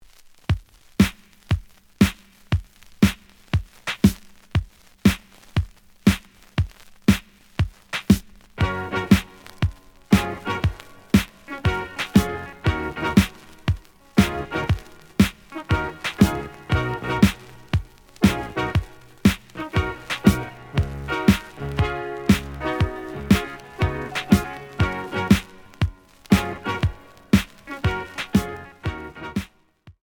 The audio sample is recorded from the actual item.
●Genre: Soul, 80's / 90's Soul
Some noise on A side.